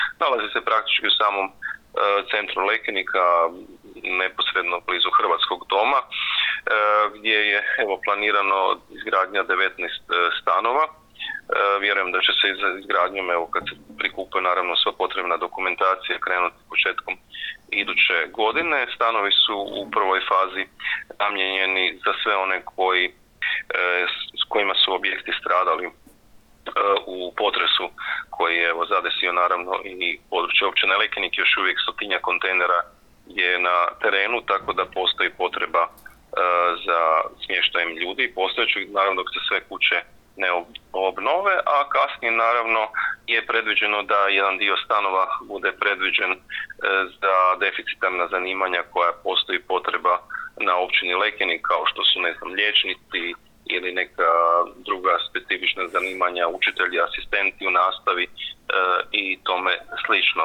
Više Ivica Perović, načelnik Općine Lekenik